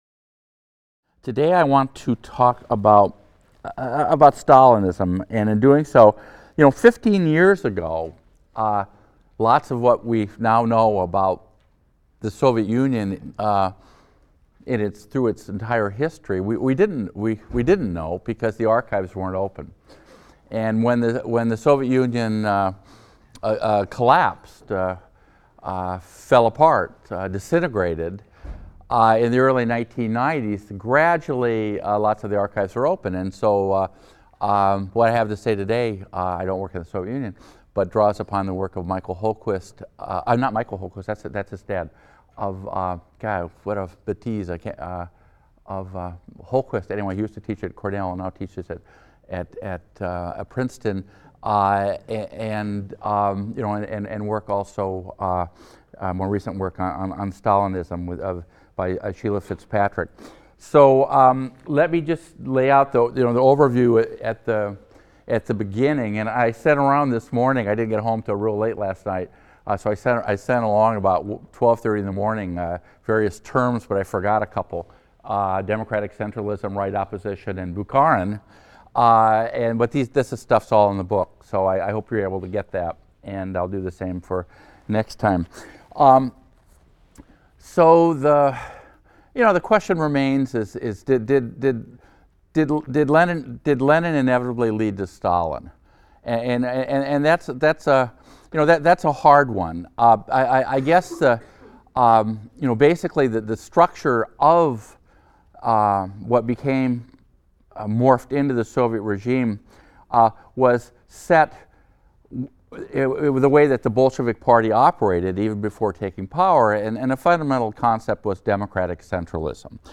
HIST 202 - Lecture 21 - Stalinism | Open Yale Courses